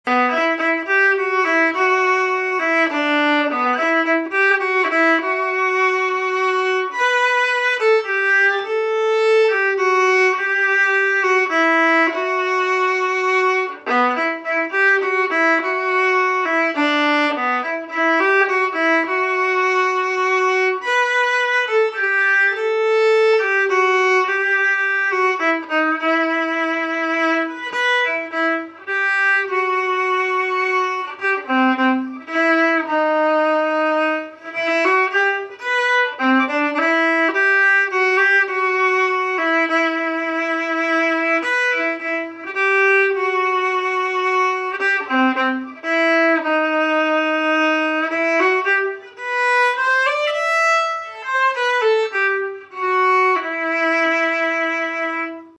Key: Em
Form: Waltz (English Country Dance)
Genre/Style: English Country Dance